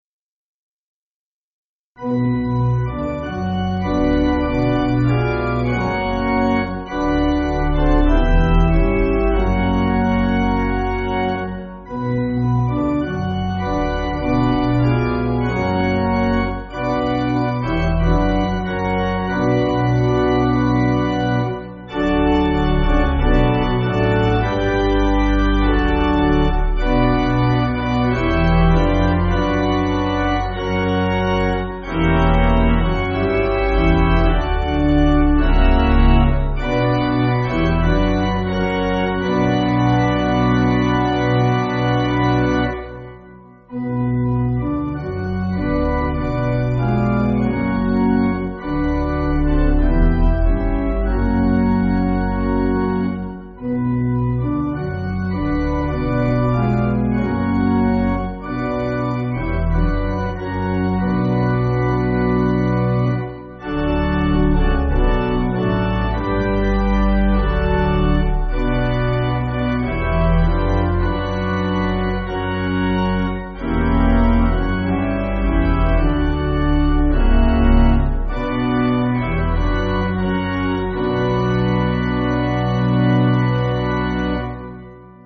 (CM)   4/Bm